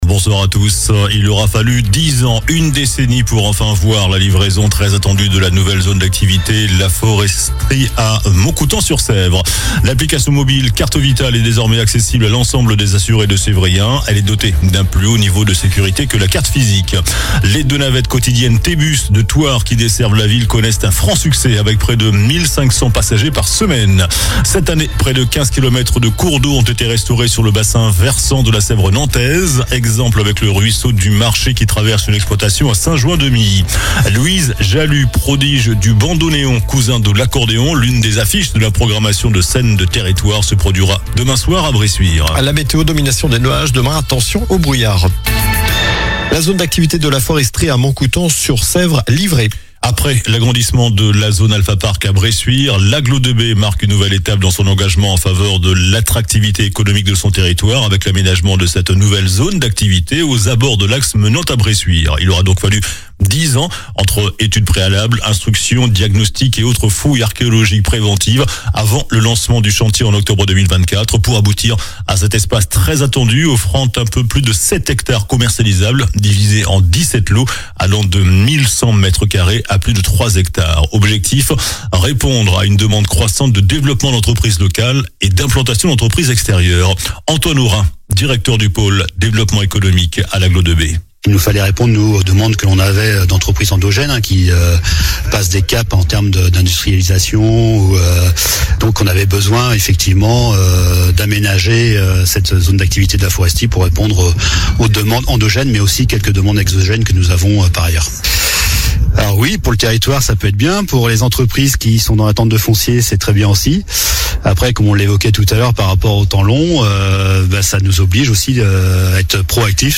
JOURNAL DU JEUDI 27 NOVEMBRE ( SOIR )